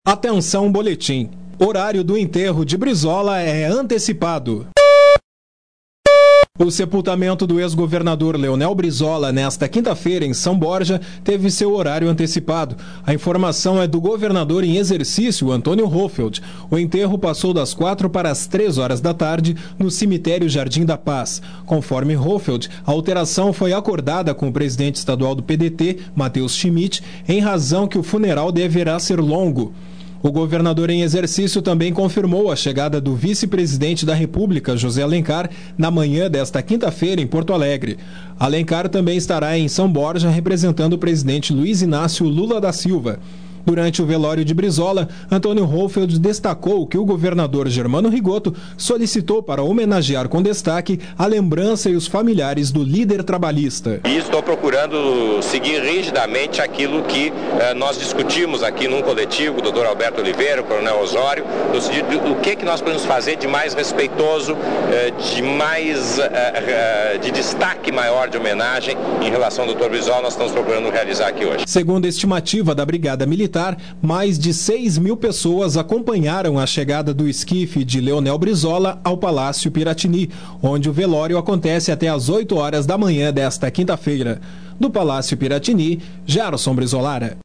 Sonora: governador do Estado em exercício, Antonio Hohlfeldt. Local: Porto Alegre-RSD